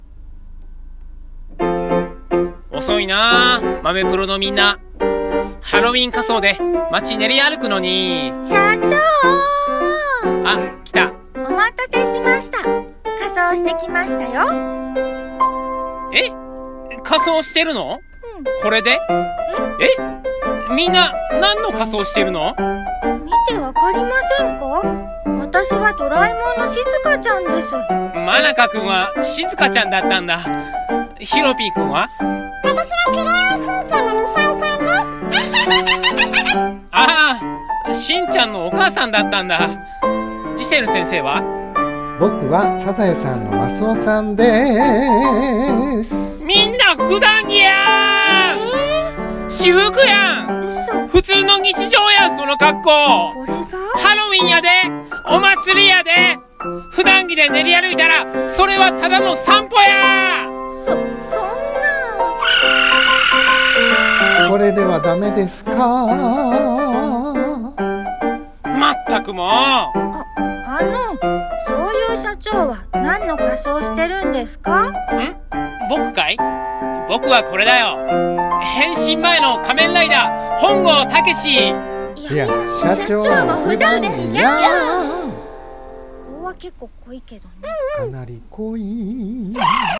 コラボ用声劇「豆プロハロウィンパーティー」